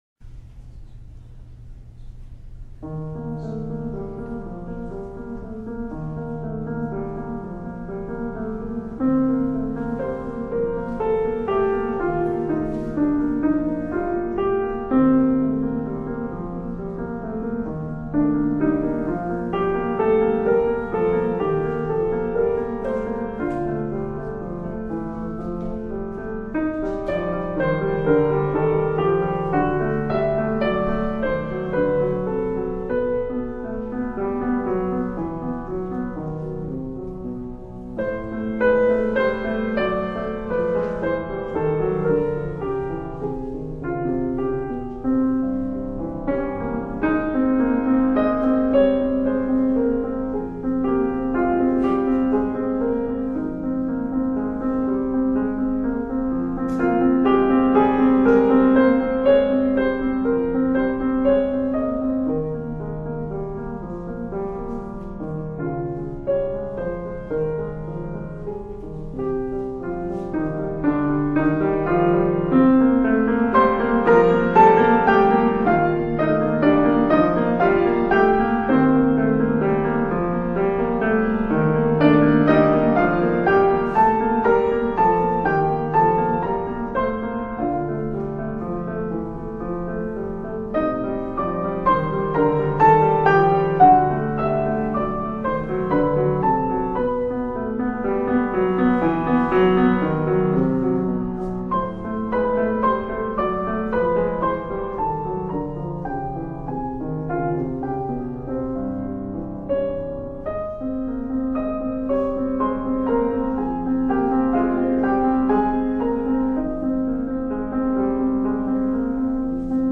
Keyboard / 2007